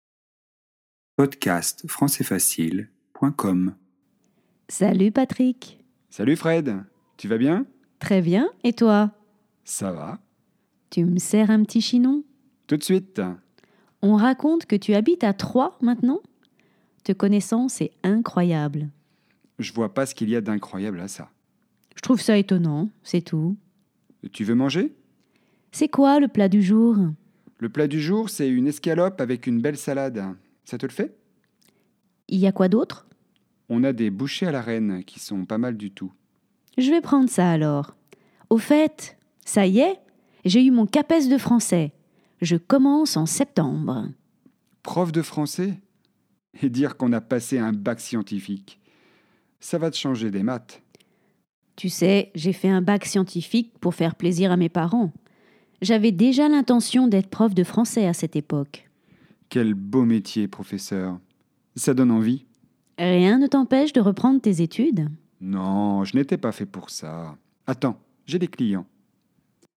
🔷 DIALOGUE :